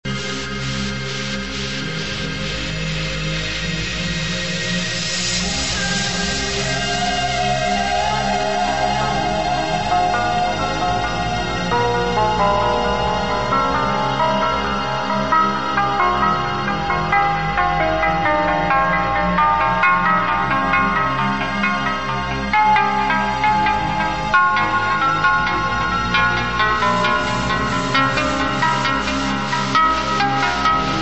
The chanting sounds familiar ..